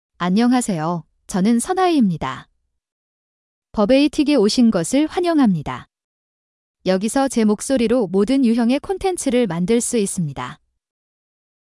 Sun-Hi — Female Korean (Korea) AI Voice | TTS, Voice Cloning & Video | Verbatik AI
Sun-Hi is a female AI voice for Korean (Korea).
Voice sample
Listen to Sun-Hi's female Korean voice.
Female
Sun-Hi delivers clear pronunciation with authentic Korea Korean intonation, making your content sound professionally produced.